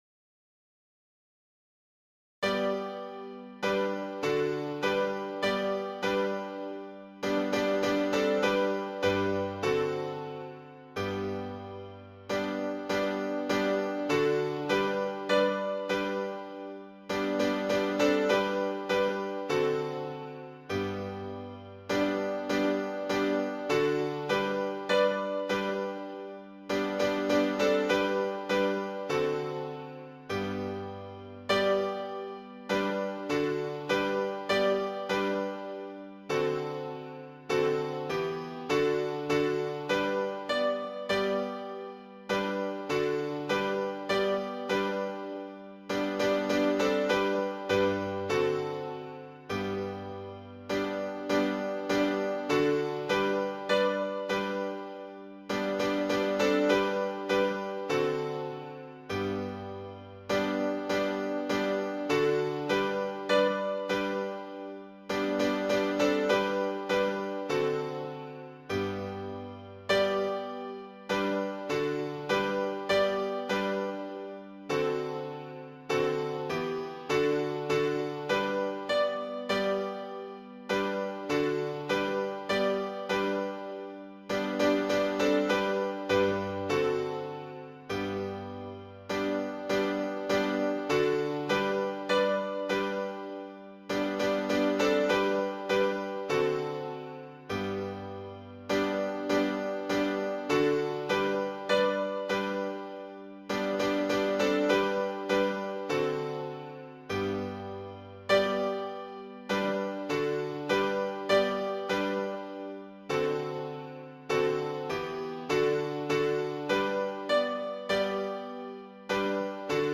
伴奏
示唱